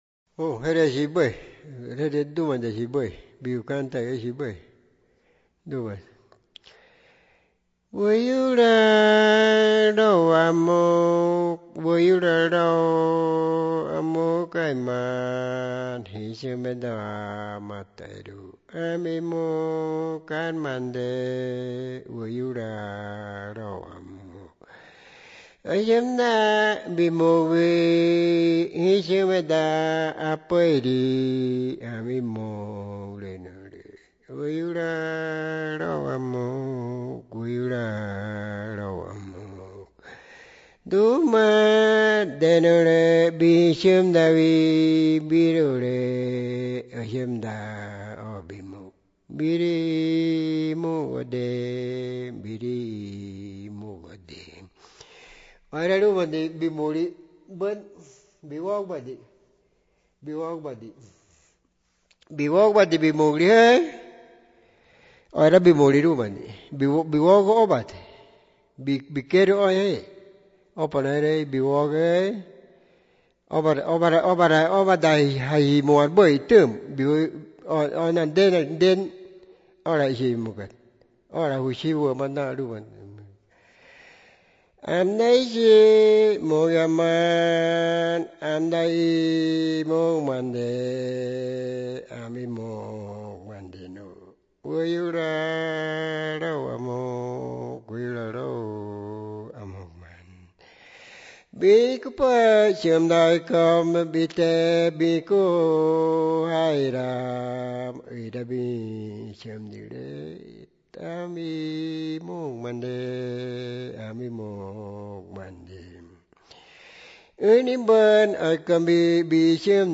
Piedra Alta, Medio Inírida, Guainía (Colombia)
El cantor o cantora le dice a la muchacha (hija del mundo femenino) que la ve triste, que también él o ella ha estado triste pero que la anima a que estén alegres tomando un poco de chicha. La grabación, transcripción y traducción de la canción se hizo entre marzo y abril de 2001 en Piedra Alta; una posterior grabación en estudio se realizó en Bogotá en 2004
The recording, transcription, and translation of the song took place between March and April 2001 in Piedra Alta; a subsequent studio recording was made in Bogotá in 2004.